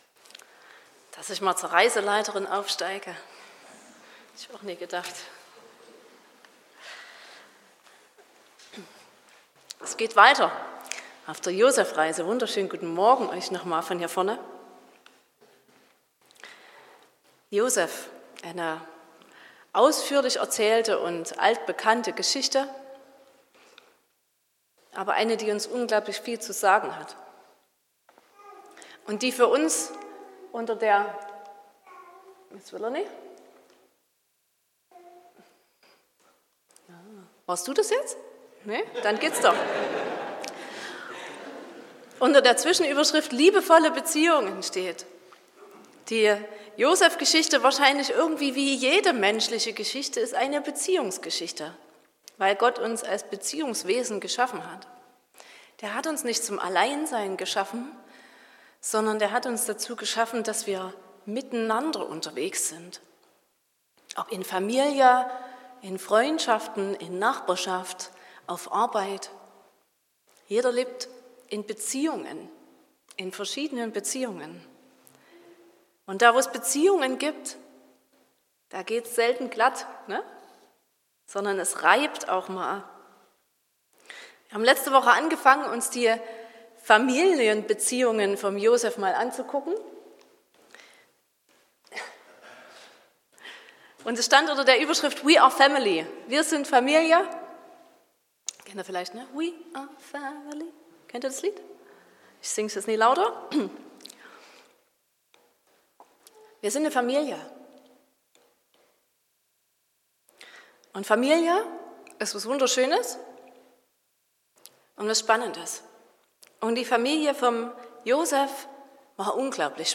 Predigt und Aufzeichnungen